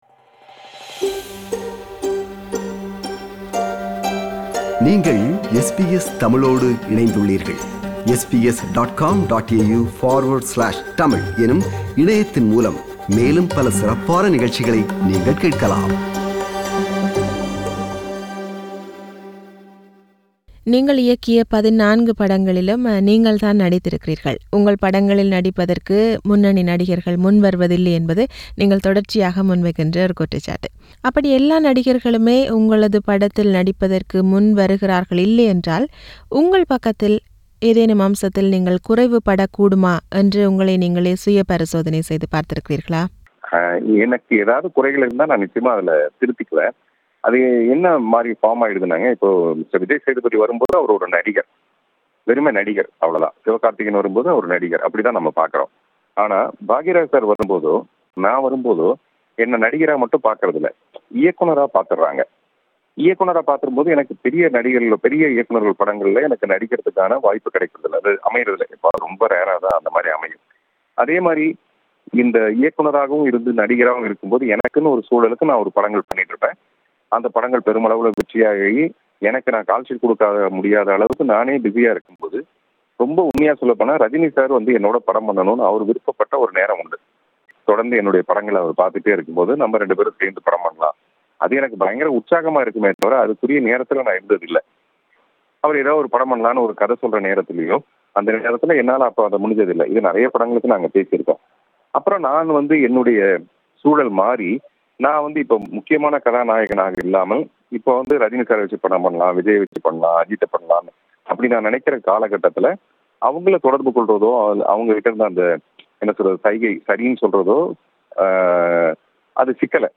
இயக்குனர், நடிகர், தயாரிப்பாளர், கவிஞர் என பன்முகம் கொண்டவர் இராதாகிருஷ்ணன் பார்த்திபன் அவர்கள். மூர்த்தி என்ற பெயர் பார்த்திபனாக மாறியது தொடக்கம் அவரது புதிய படம்வரை நாம் எழுப்பிய பல கேள்விகளுக்கு அவர் பதிலளிக்கிறார்.